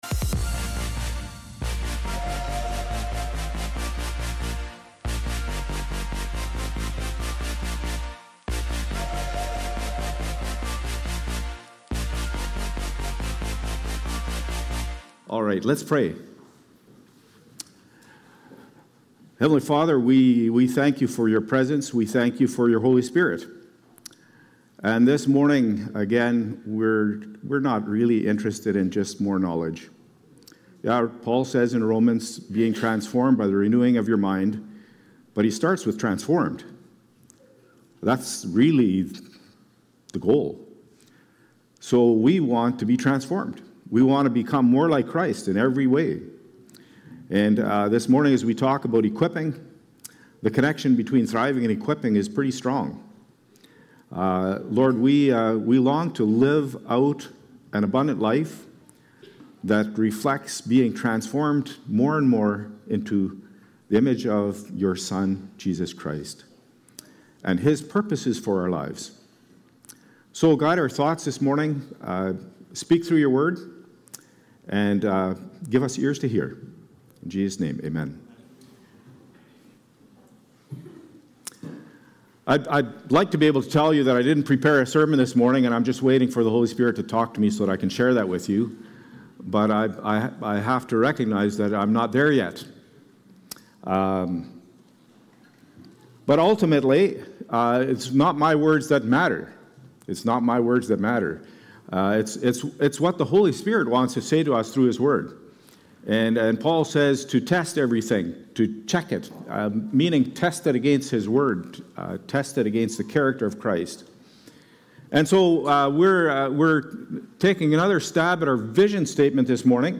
Oct-12-Worship-Service.mp3